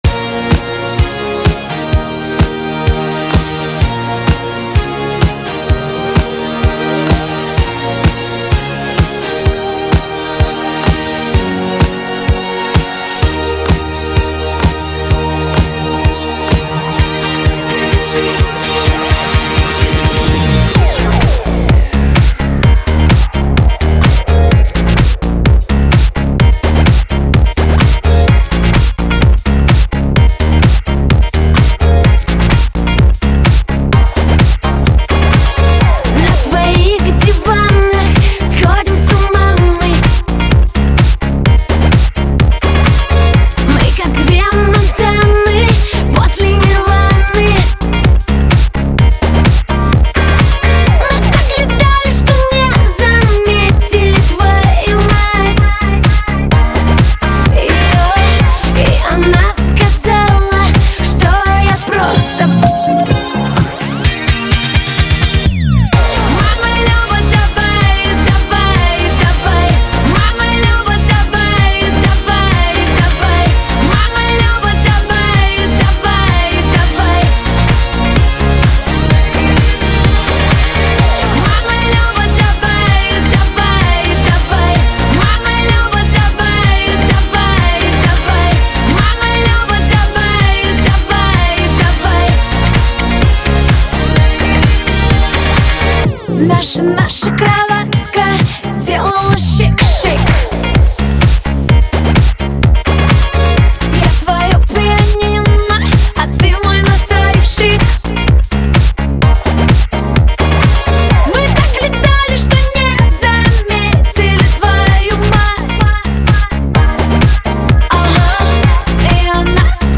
Категория: Попсовые песни